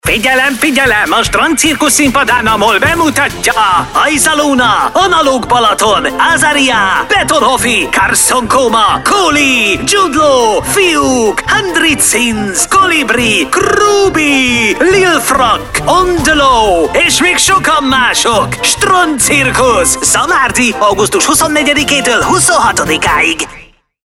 Commerciale, Jeune, Enjouée, Amicale, Chaude